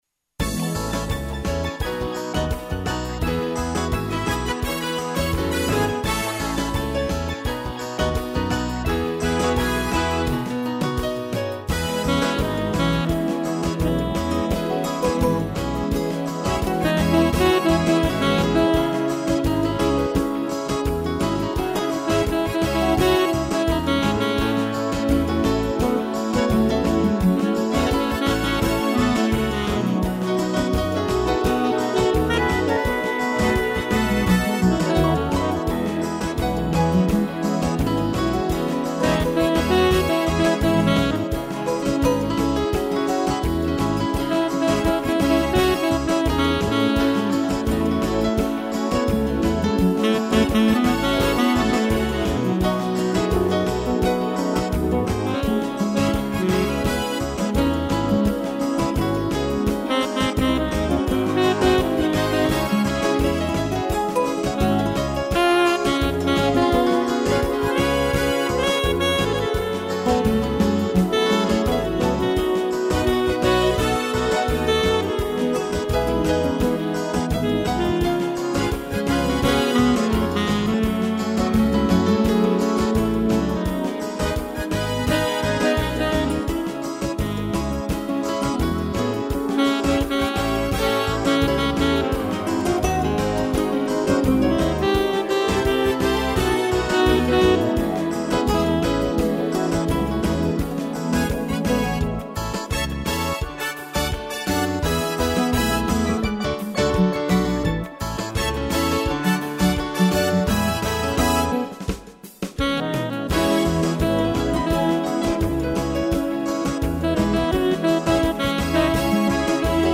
piano
violão